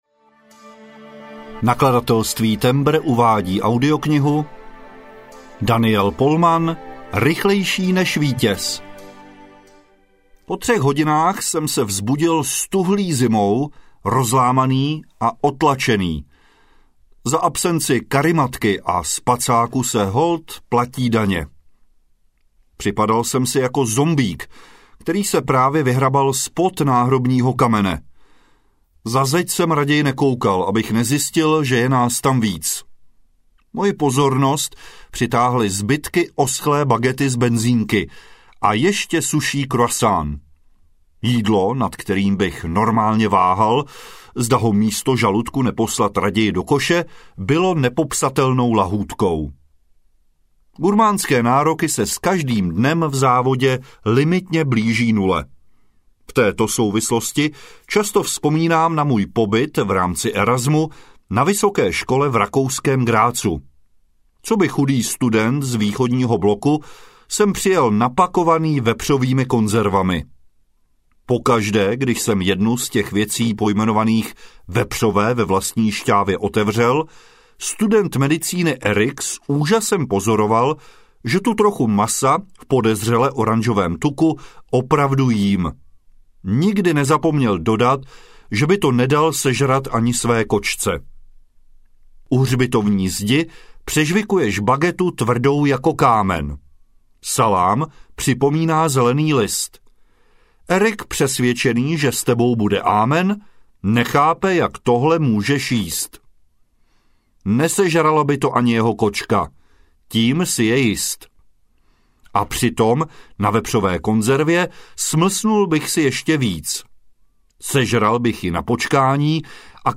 Rychlejší než vítěz audiokniha
Ukázka z knihy
rychlejsi-nez-vitez-audiokniha